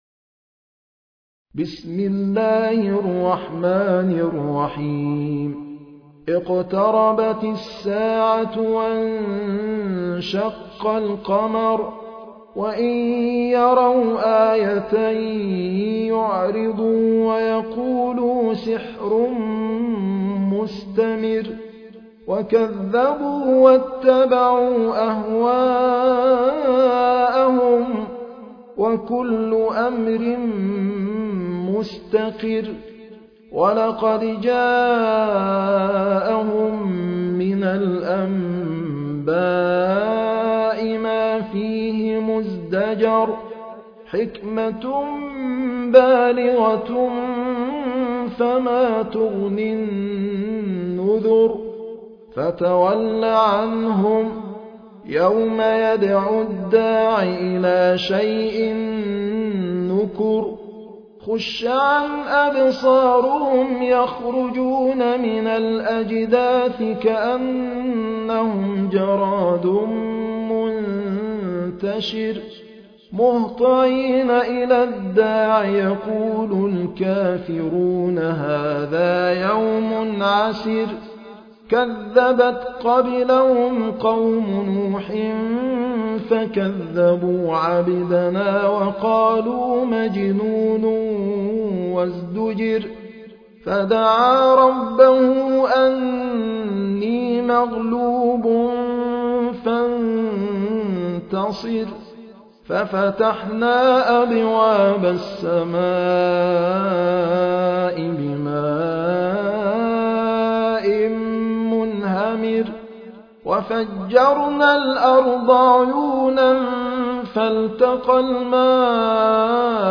المصحف المرتل - حفص عن عاصم - Al-Qamar ( The Moon )